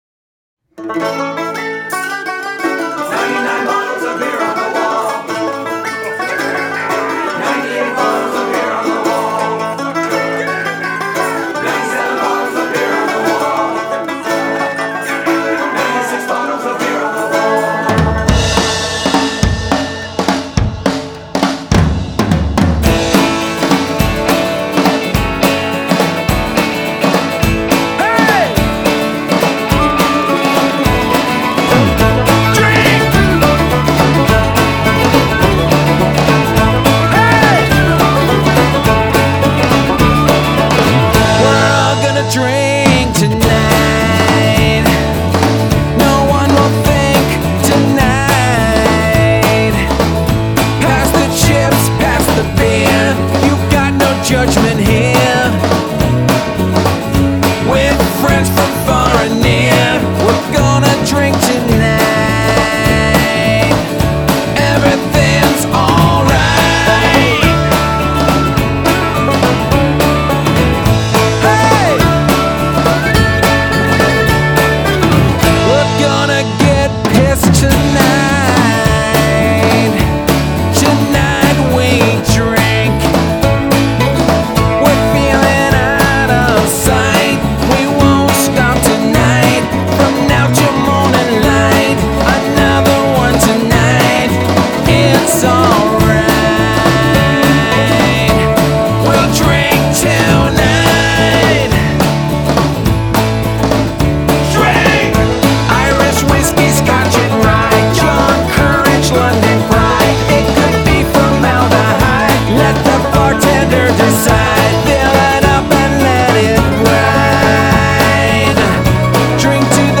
Irish-inflected